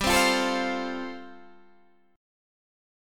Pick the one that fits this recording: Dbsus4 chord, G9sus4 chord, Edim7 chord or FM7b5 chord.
G9sus4 chord